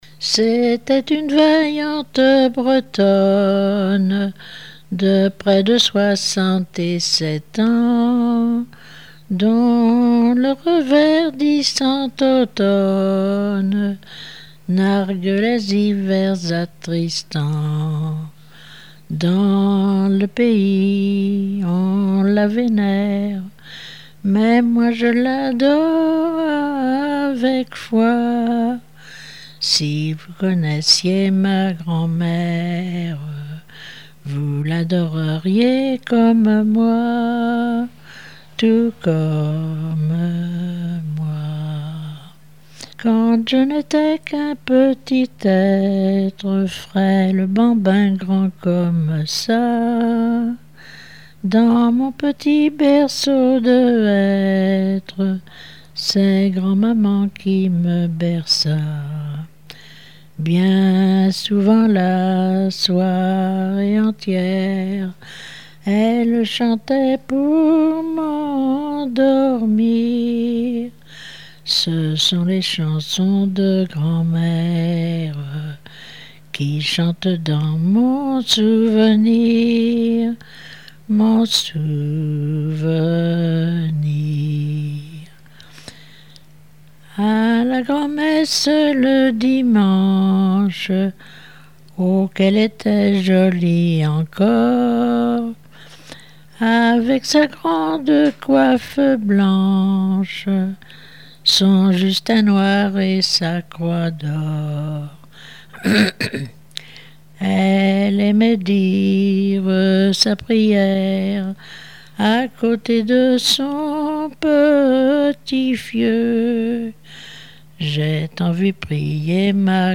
répertoire de chansons populaires de variété
Pièce musicale inédite